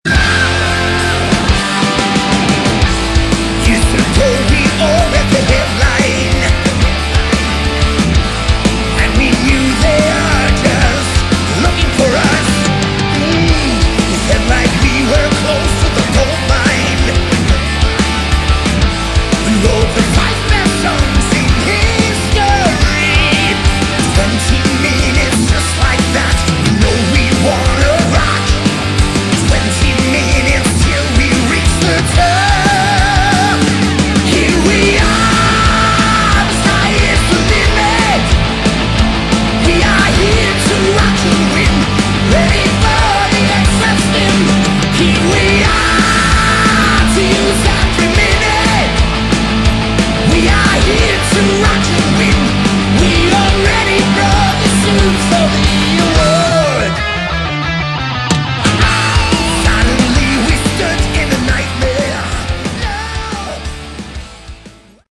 Category: Melodic Metal
vocals
lead guitars
rhythm guitars
bass
drums